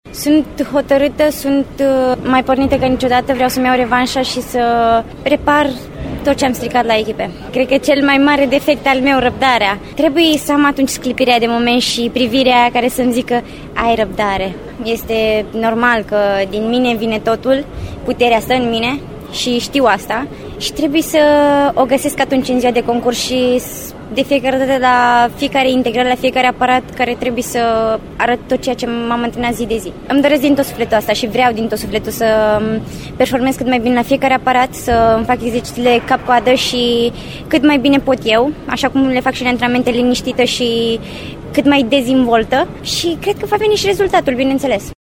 Ascultaţi-o pe Larisa înaintea testului maturităţii: